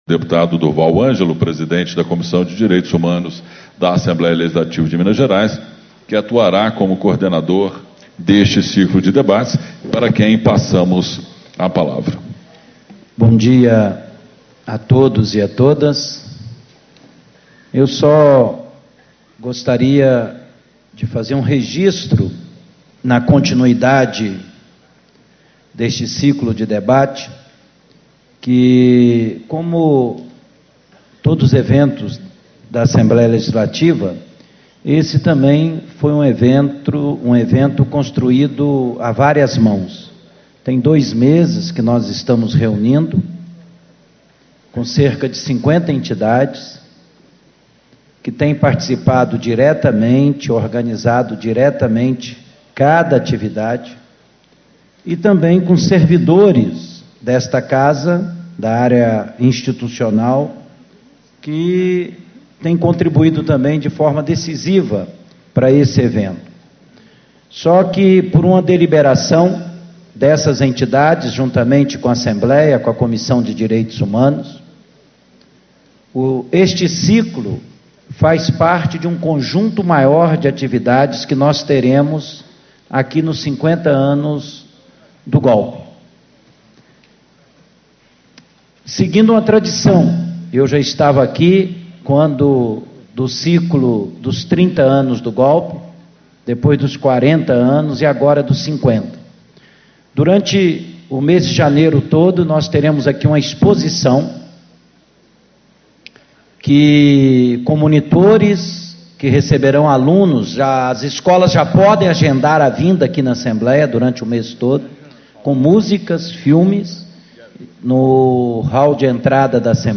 Abertura - Deputado Durval Ângelo, PT - Presidente da Comissão de Direitos Humanos da Assembleia Legislativa do Estado de Minas Gerais
Discursos e Palestras